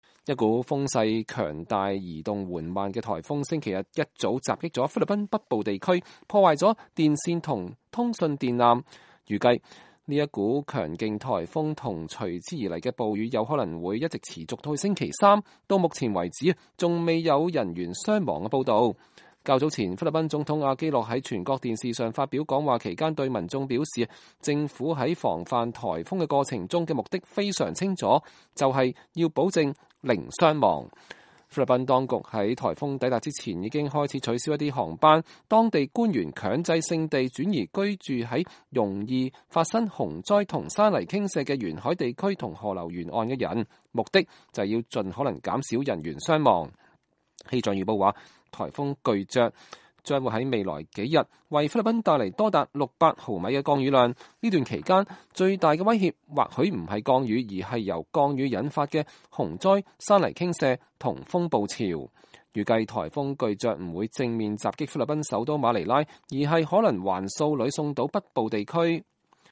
菲律賓氣象局預報員介紹颱風巨爵